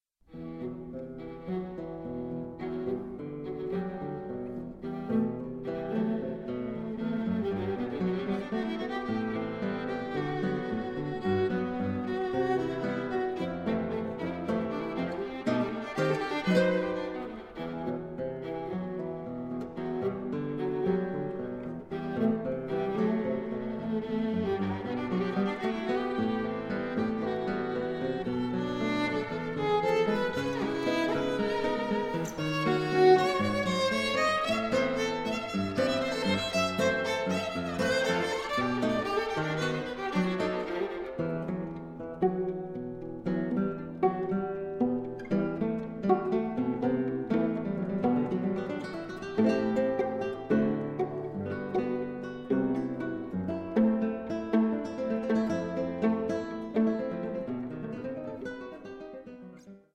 viola
guitar